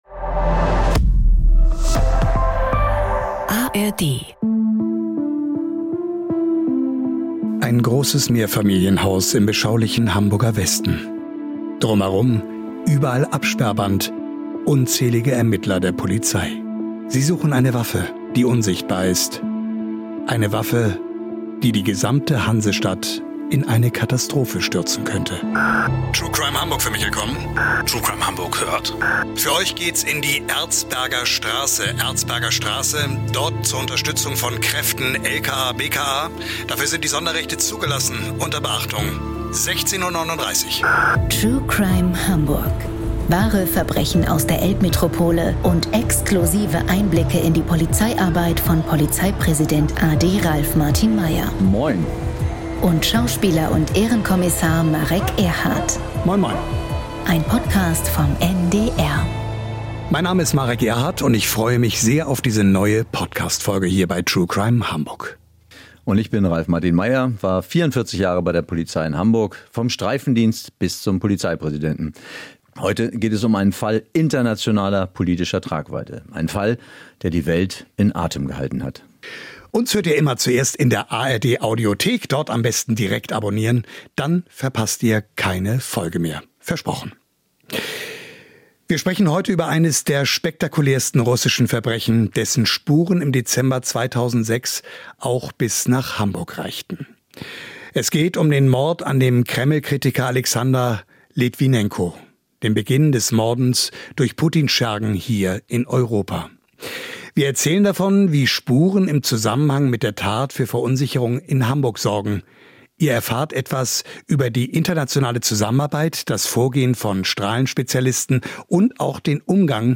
Ralf Martin Meyer und Marek Erhardt erzählen, wie die Polizei mit der unsichtbaren Gefahr umging, und welche Brisanz dieser Fall bis heute birgt.